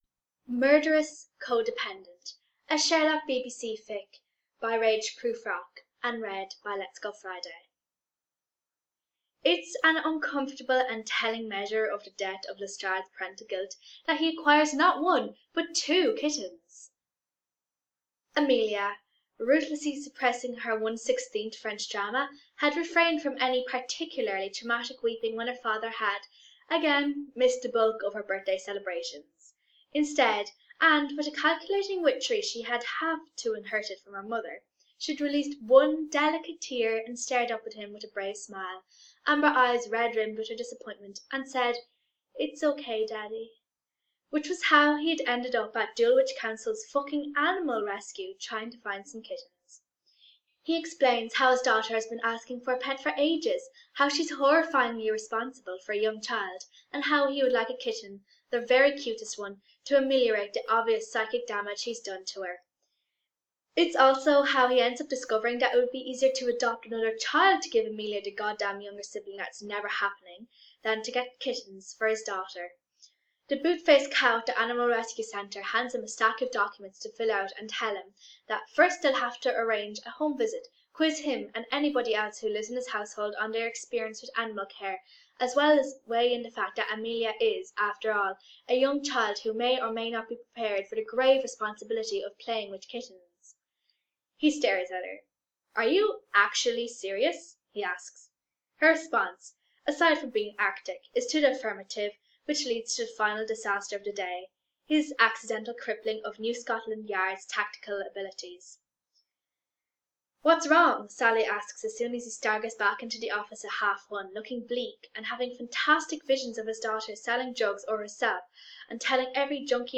Sherlock Podfic